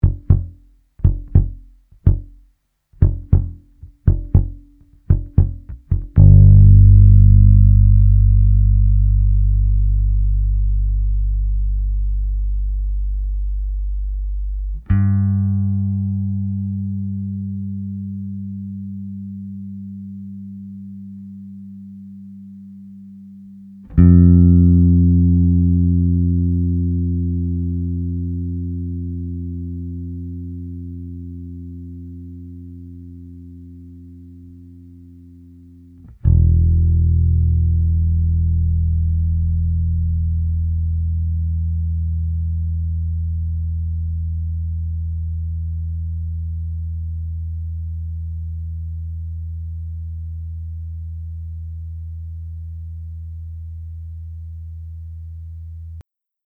Sobald das Relay und der LeBass zusammen in der Signalkette hängen habe ich ein merkwürdiges Rauschen das beginnt am Anfang eines Tones um dann innerhalb dieses Tones irgendwann zu verschwinden. So als ob ein Compander einen gewissen Schwellenwert unterschreitet und dann irgendwann aufhört aktiv ins Signal einzugreifen. Beim nächsten Ton ist es dann wieder da...